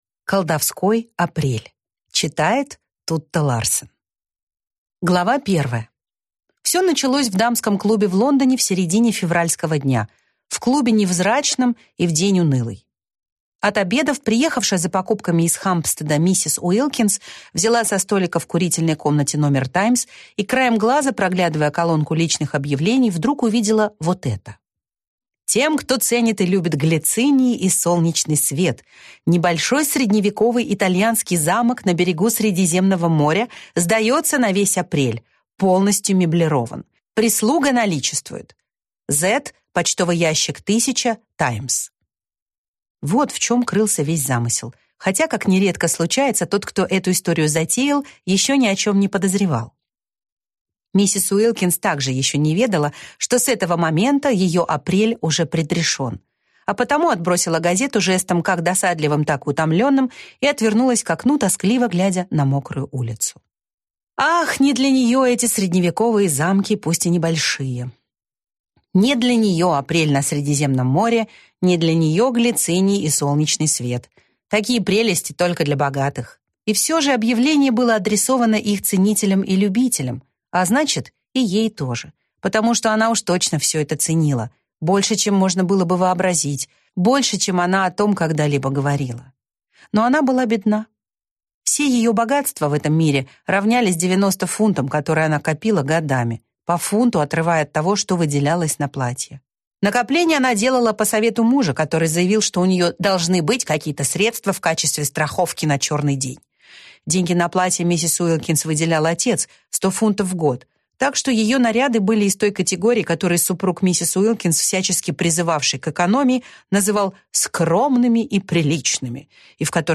Аудиокнига Колдовской апрель | Библиотека аудиокниг